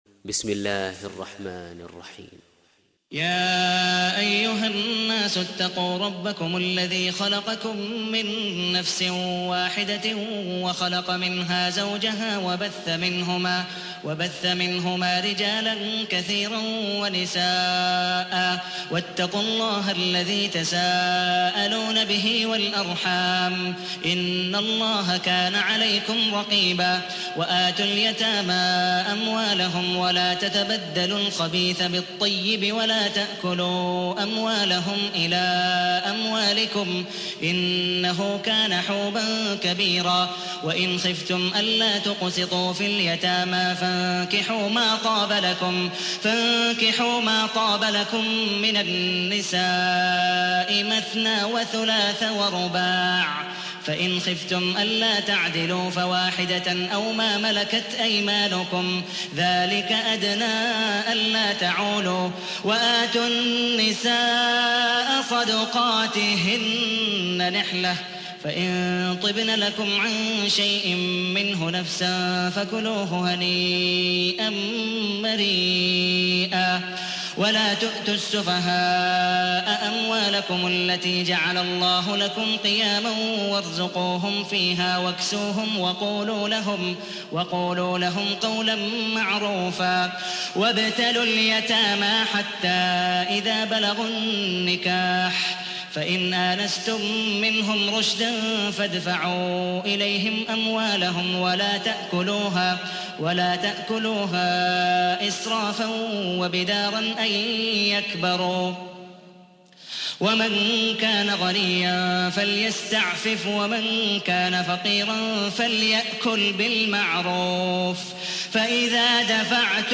تحميل : 4. سورة النساء / القارئ عبد الودود مقبول حنيف / القرآن الكريم / موقع يا حسين